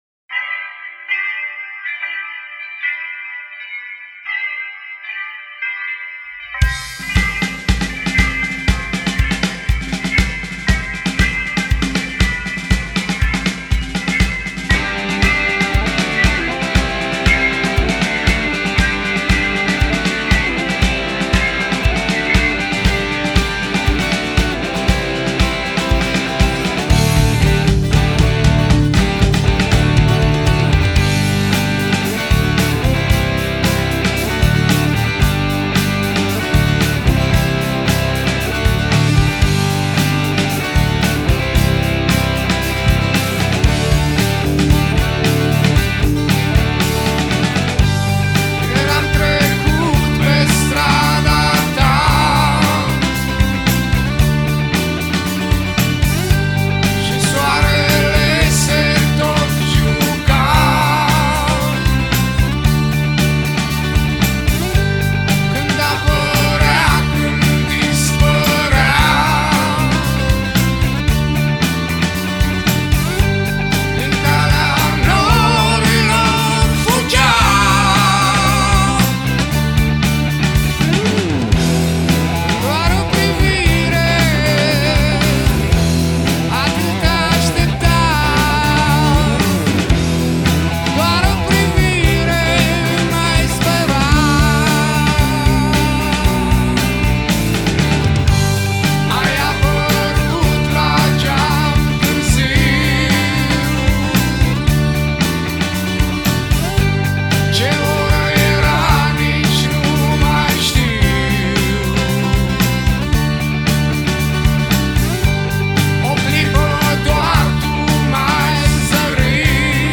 un aniversat „de top” al muzicii rock din România